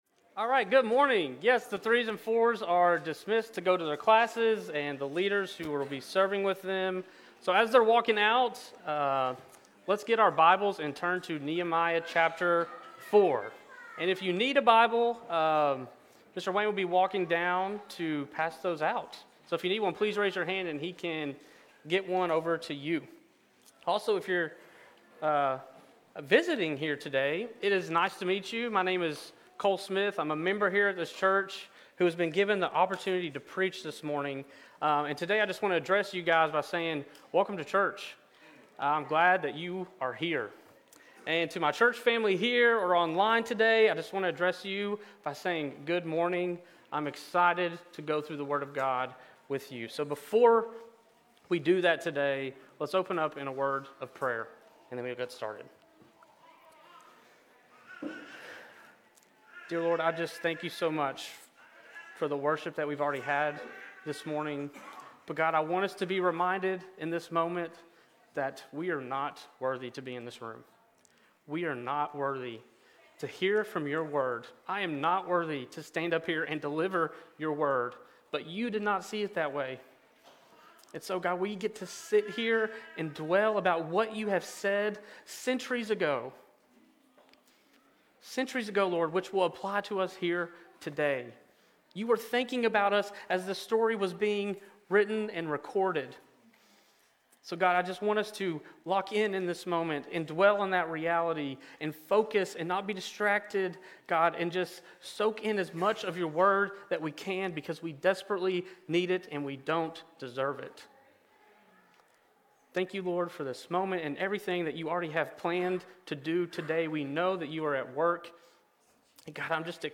Sermons | St. Rose Community Church